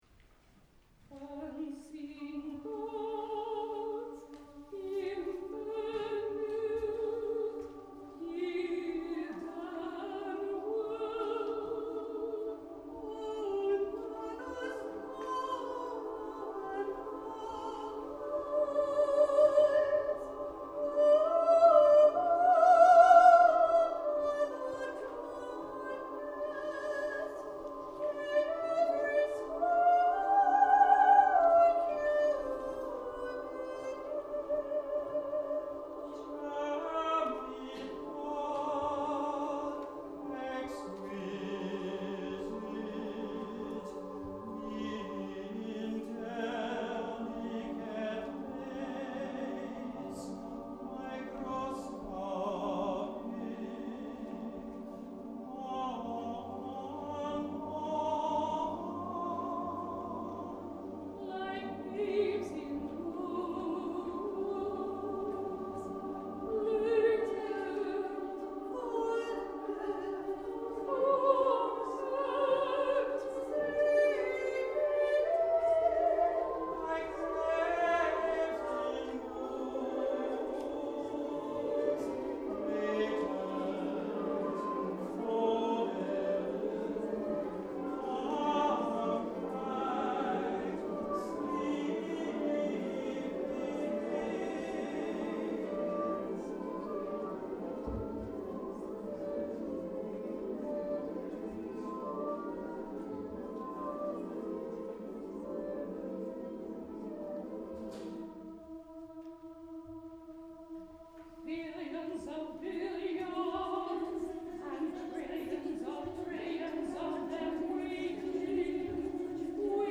Voicing: SAT soli, SATB [divisi]